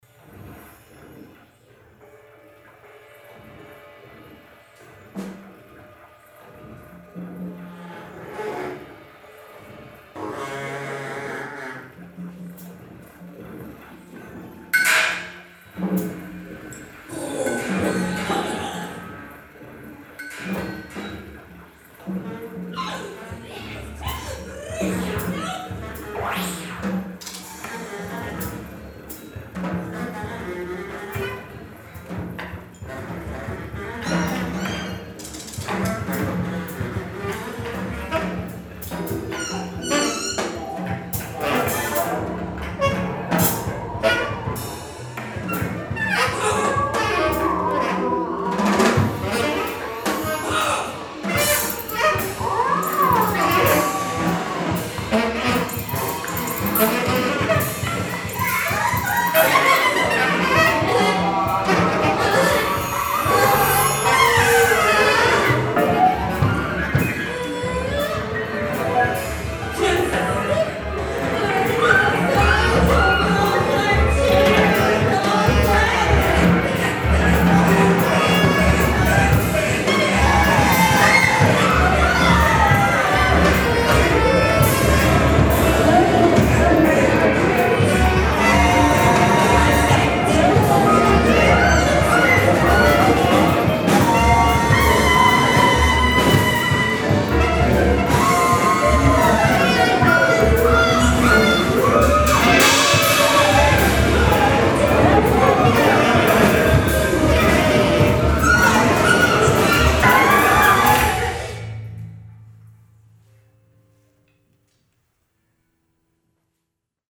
Sax
Bass-Klarinette
Klavier
E-Bass
Gitarre
Schlagzeug
location: Kunststation, Wuppertal-Vohwinkel